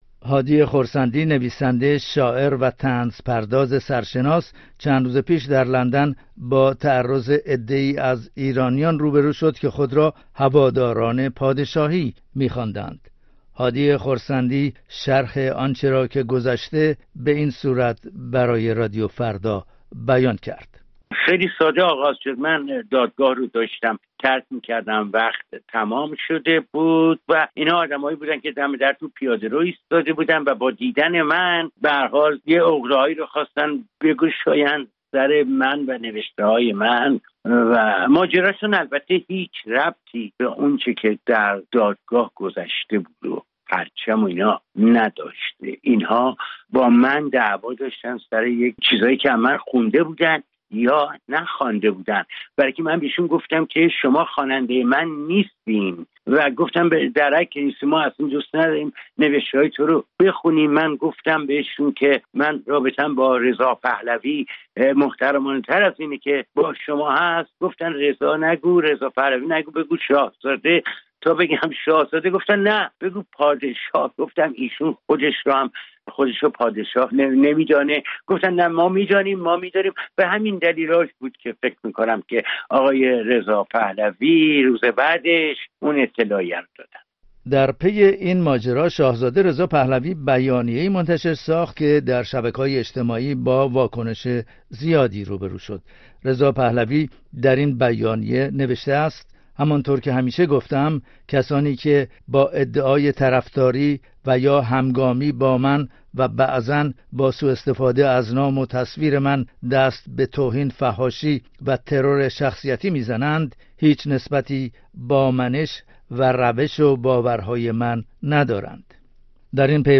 عده‌ای که خود را سلطنت‌طلب می‌خواندند، به هادی خرسندی، شاعر و طنزپرداز، هنگام خروج از دادگاه ایران تربیونال برای کشتار آبان ۹۸، تعرض و بی‌احترامی کردند. گزارشی در این زمینه بشنوید.